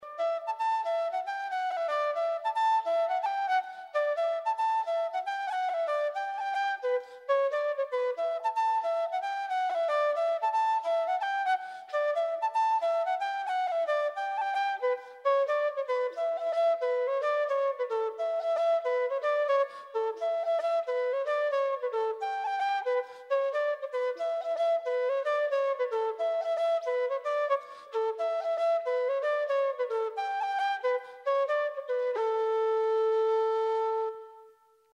Traditional Irish Music -- Learning Resources Ellen O'Grady (Slip Jig) /